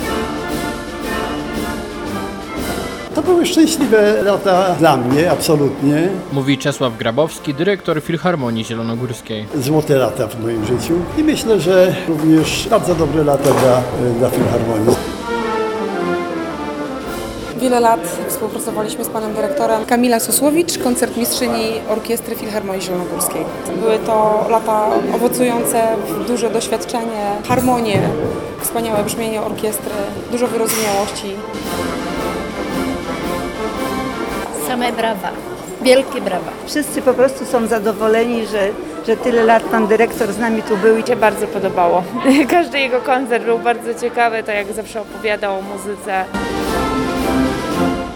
Na sali sali koncertowej oraz przy telebimie, który znajdował się przed wejściem głównym do placówki zebrało się kilkuset zielonogórzan. Zagrano popularne utwory symfoniczne, a następnie osobistości, pracownicy i inni mieszkańcy Zielonej Góry składali podziękowania za lata pracy: